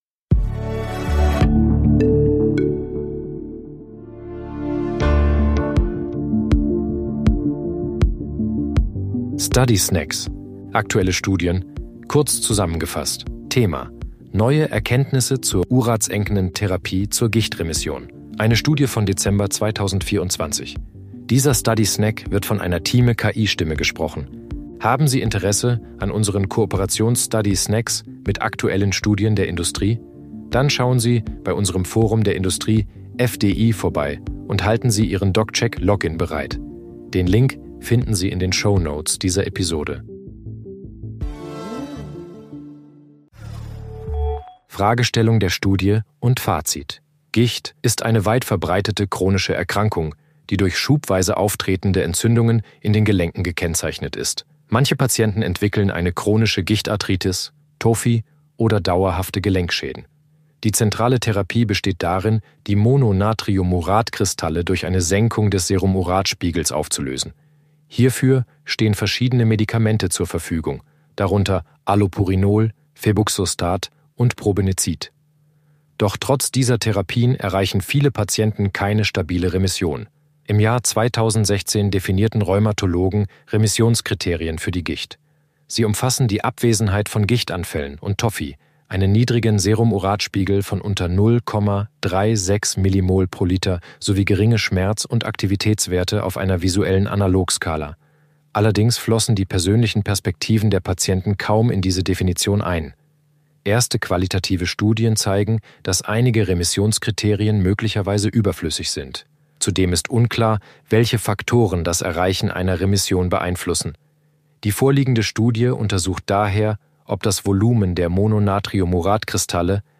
sind mit Hilfe von künstlicher Intelligenz (KI) oder maschineller
Übersetzungstechnologie gesprochene Texte enthalten